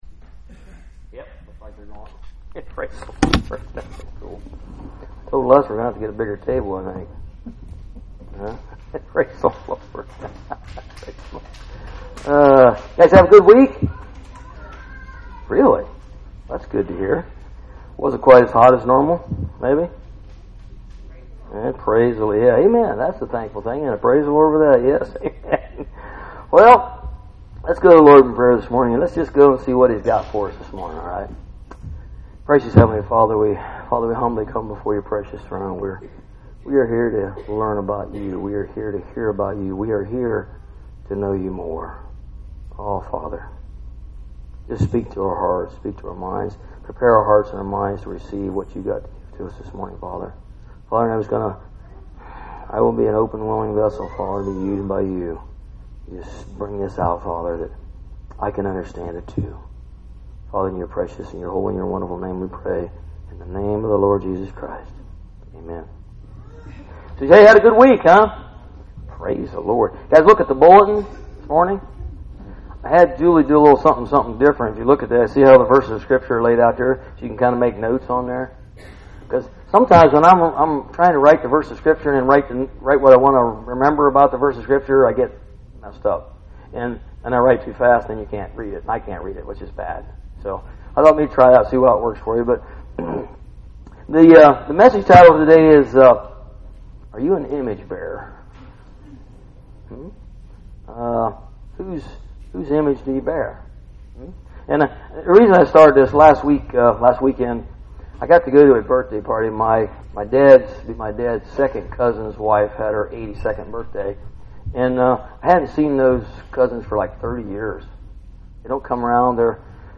Bible Text: 1 John 2:15-17, Philippians 2:5-9, Galatians 5:22-26, Philippians 3:7-10, Hebrews 11:33-37, Daniel 3:16-18 | Preacher